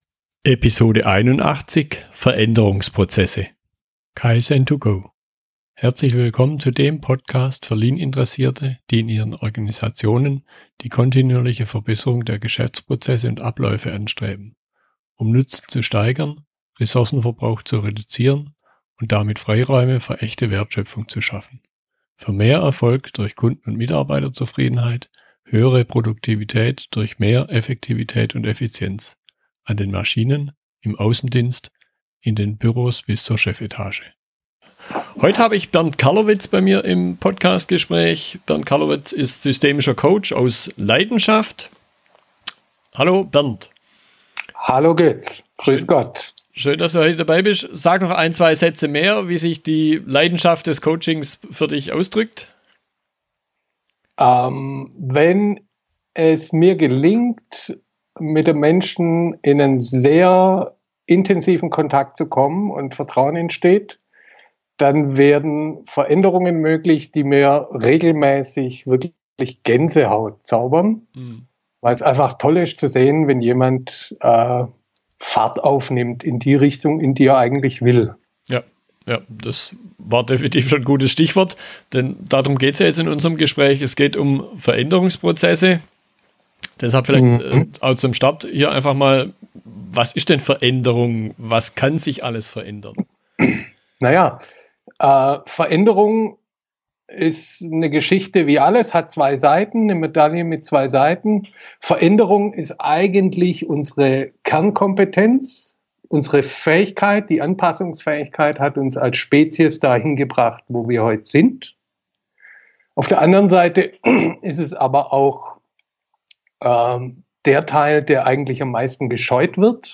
Wir unterhalten uns darüber, warum Widerstand gegen Veränderung entsteht und wie damit umgegangen werden kann.